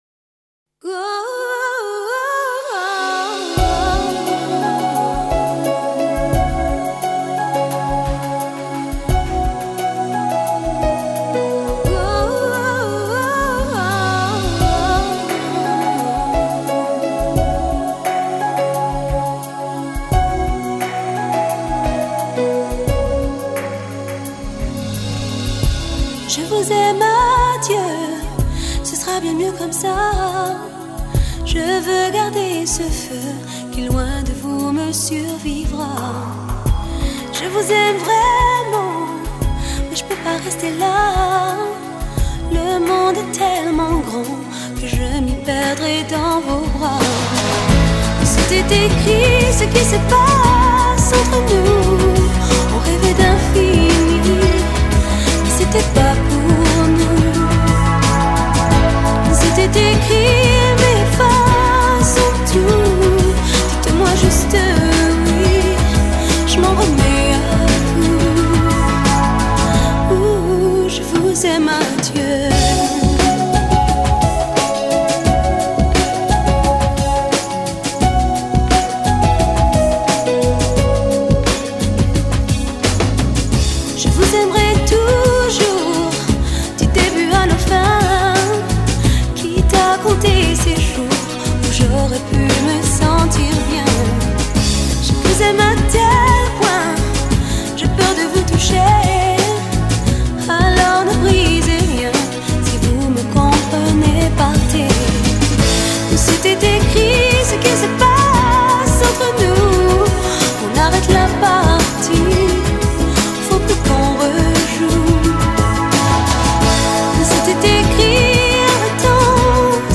法国香颂